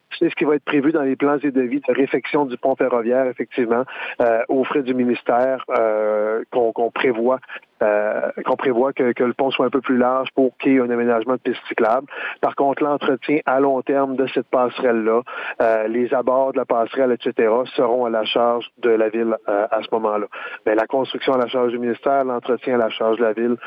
Daniel Côté donne quelques précisions sur l’accord signé entre la Ville et le MTQ.